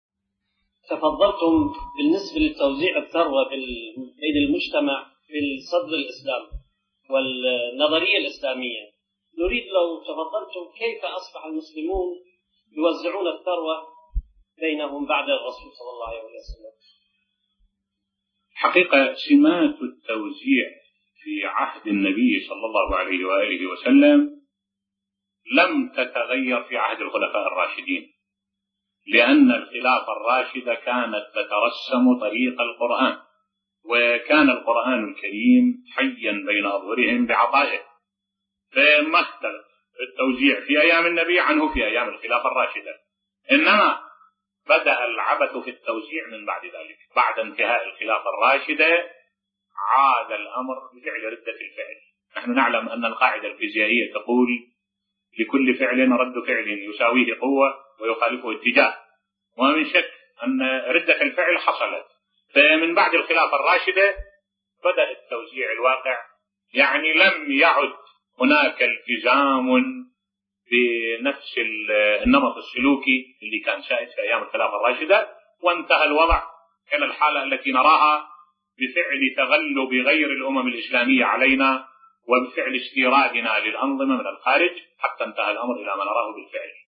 ملف صوتی كيف كانت توزع الثروة في عهد النبي الأعظم (ص) بصوت الشيخ الدكتور أحمد الوائلي